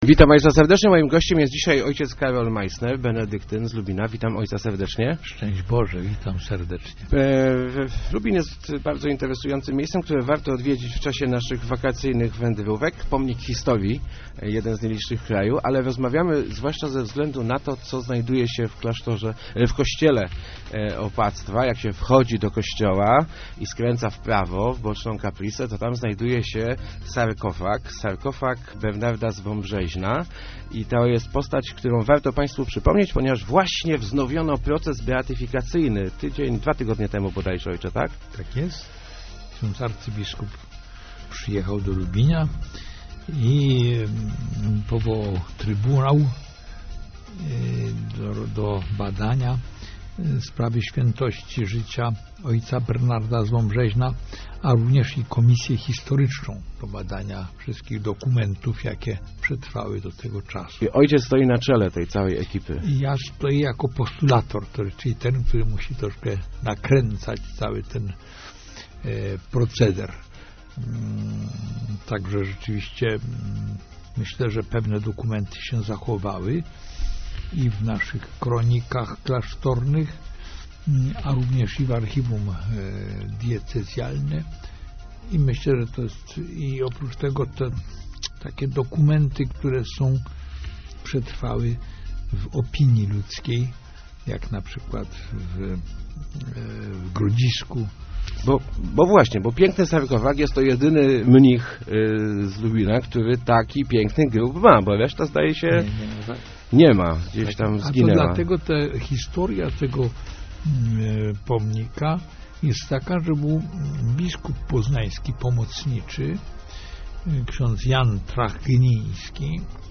Klasztor w Lubiniu może już wkrótce doczekać się swojego błogosławionego. Wznowiono bowiem proces beatyfikacyjny Bernarda z Wąbrzeźna, mnicha żyjącego w XVII wieku. Mimo upływu czasu jego kult jest wciąż bardzo silny - mówił w Rozmowach Elki benedyktyn